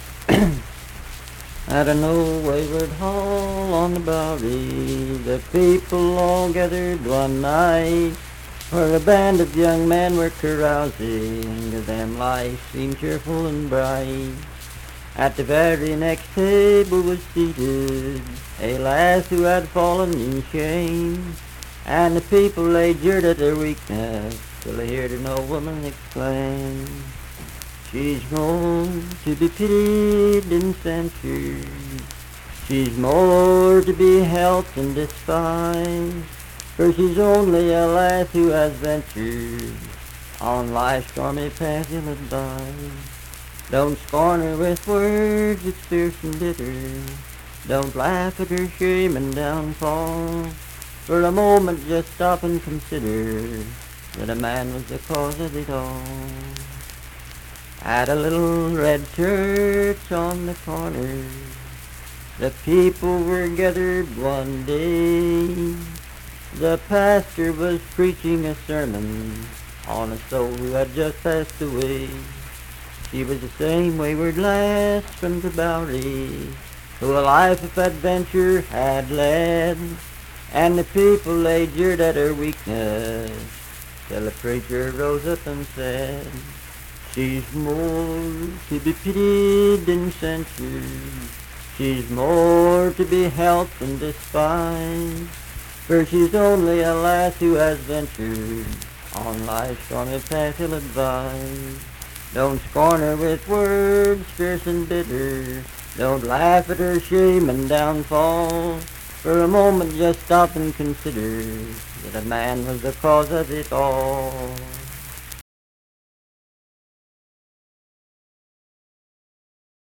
Unaccompanied vocal music
Verse-refrain 2(8)&R(8).
Voice (sung)